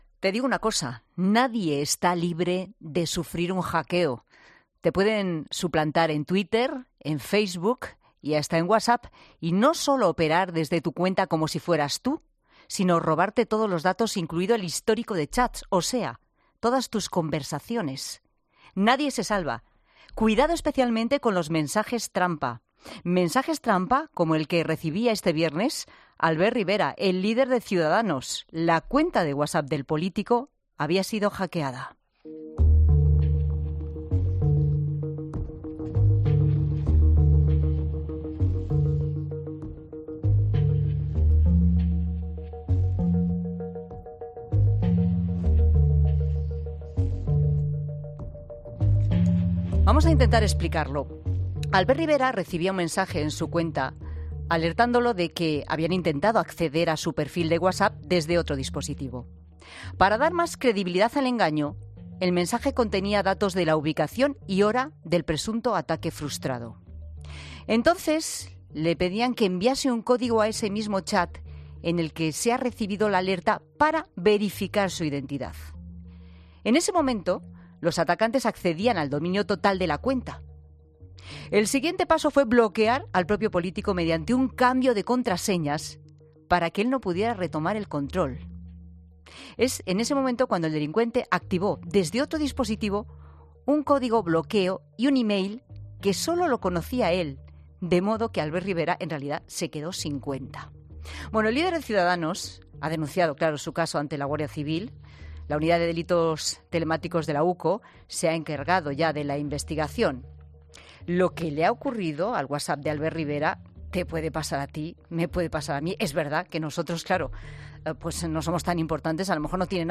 Albert Rivera denuncia que su cuenta de WhatsApp ha sido hackeada. Hablamos con el experto en ciberseguridad